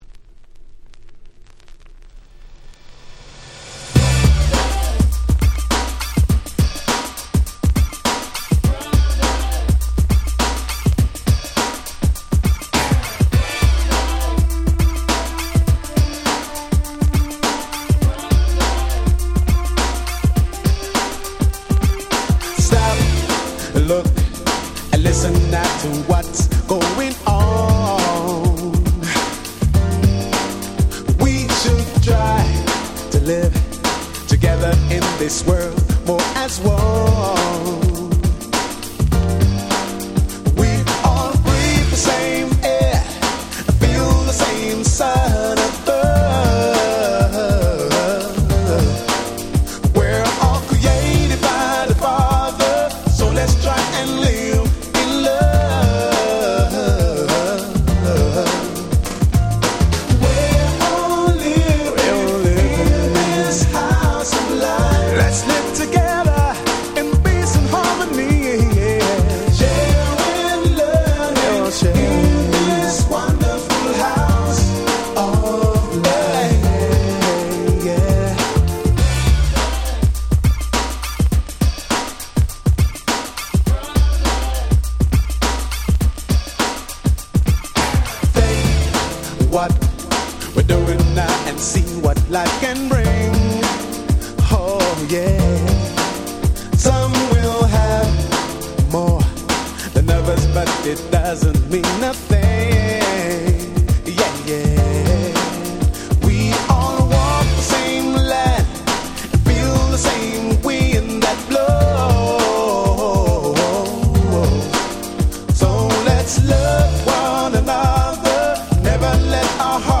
92' Very Nice UK R&B Album !!
甘い歌声が心地良いキャッチーなUK Soul / R&Bナンバーがてんこ盛りの良作です！！